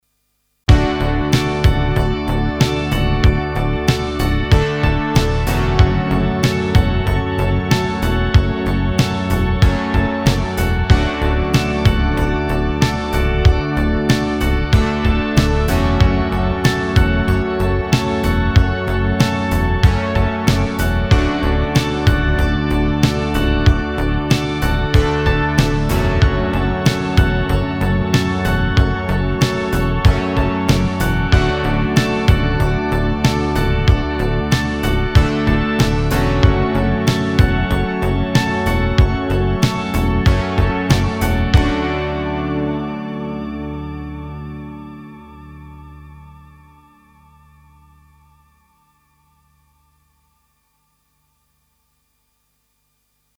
Hier noch ein Beispiel im Kontext: Anhang anzeigen 236804 Der Synth erzeugt die große Fläche. Bis auf ein Element auch alles vintage. 5-Minuten-Vorproduktion einschließlich (deutlich färbender) Mixbus-Insert-Kette.